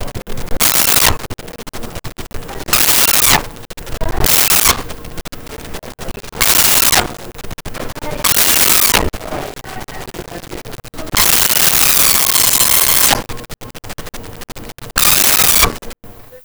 Kitten Meows 01
Kitten Meows 01.wav